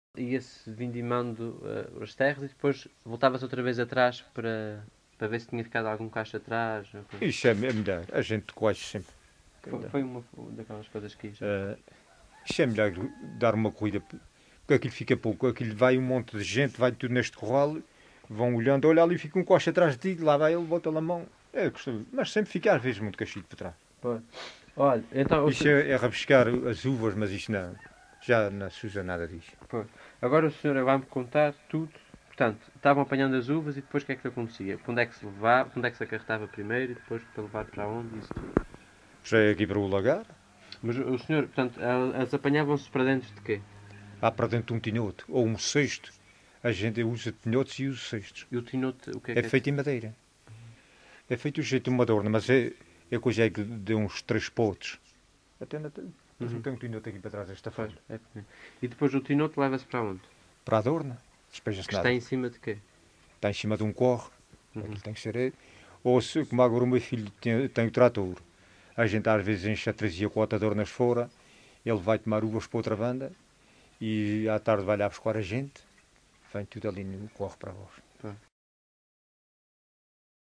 LocalidadeCovas (Santa Cruz da Graciosa, Angra do Heroísmo)